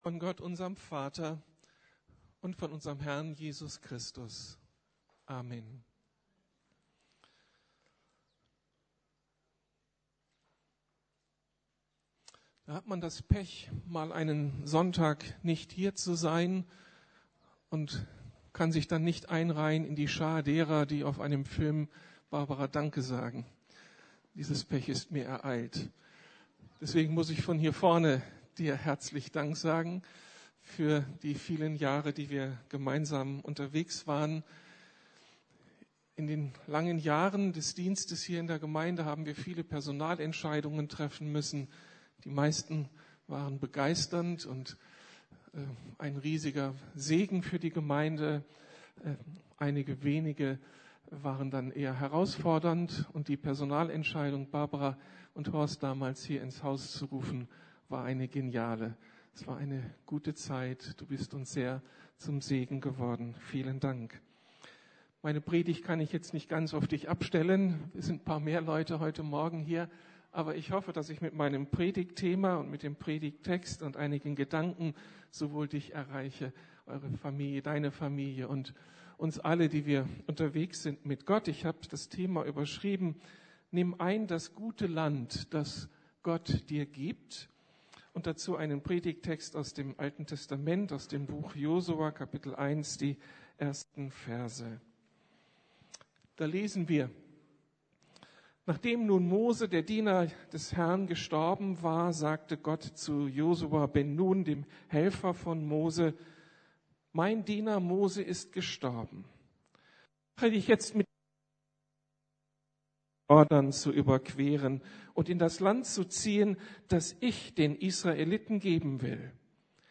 Nimm ein das gute Land, das Gott Dir gibt ~ Predigten der LUKAS GEMEINDE Podcast